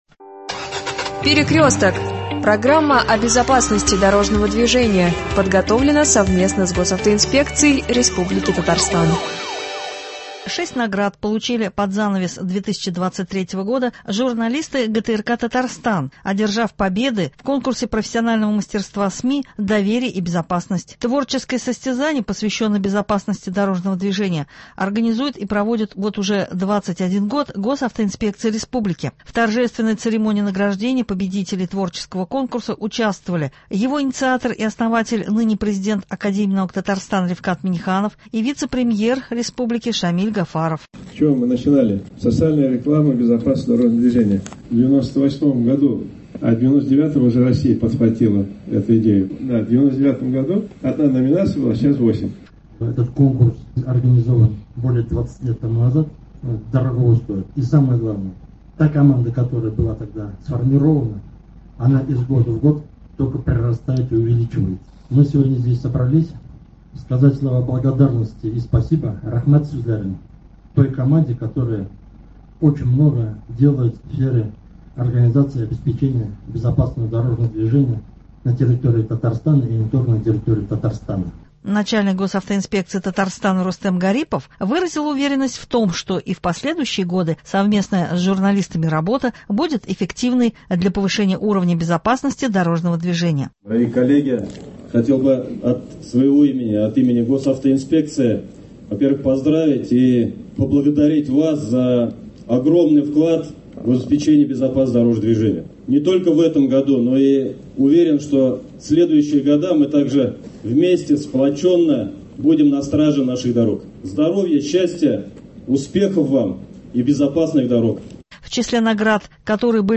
Далее в программе – интервью